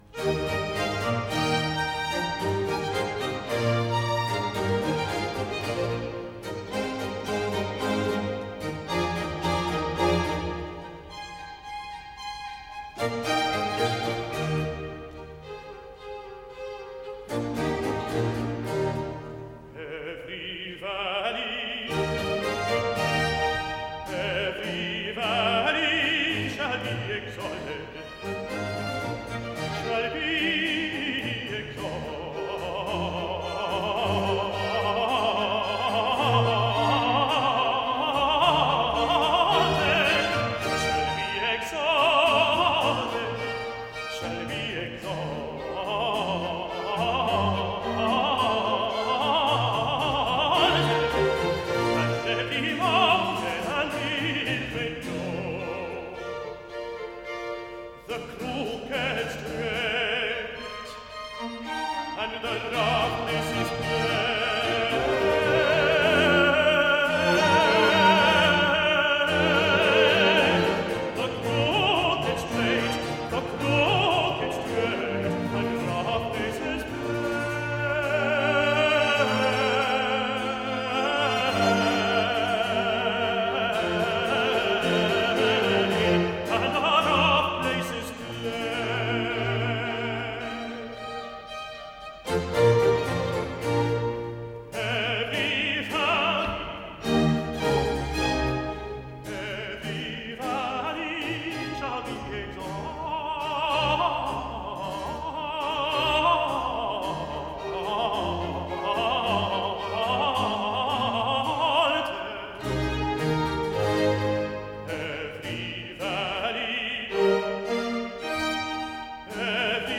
Aria-tenor